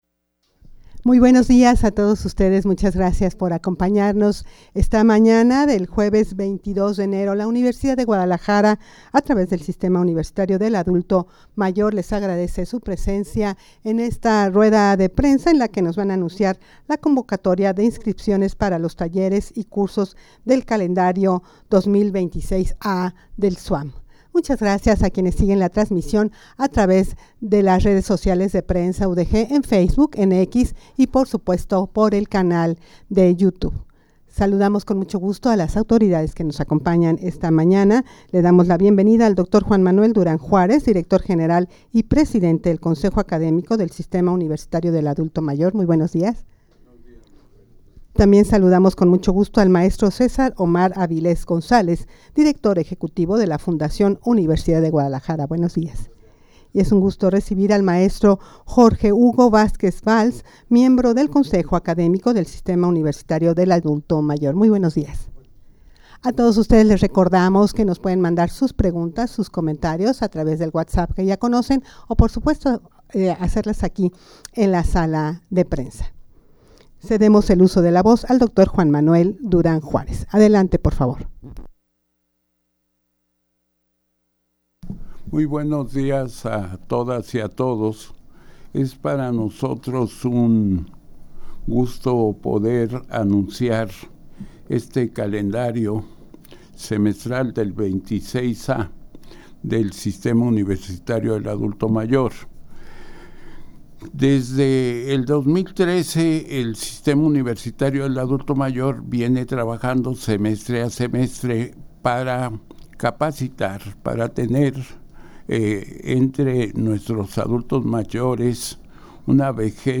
Audio de la Rueda de Prensa
rueda-de-prensa-para-anunciar-su-convocatoria-de-inscripciones-para-los-talleres-y-cursos-del-calendario-2026-a.mp3